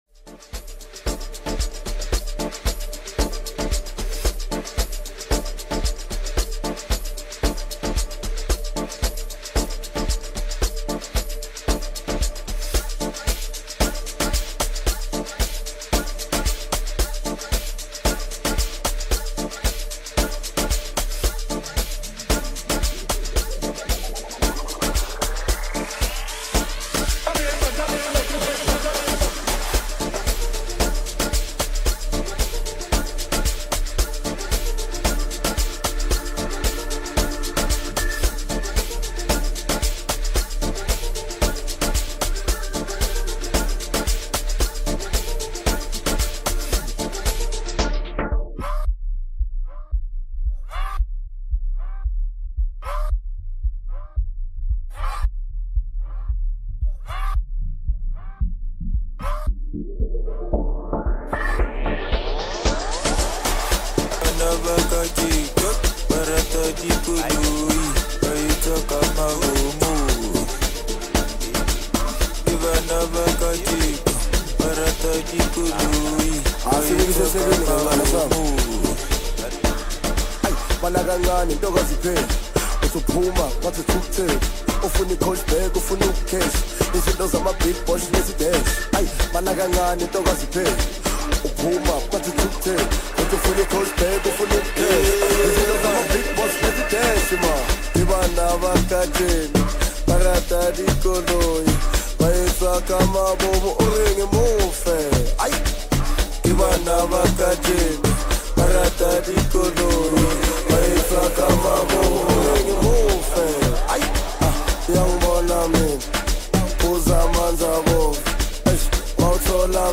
Home » Amapiano » DJ Mix » Lekompo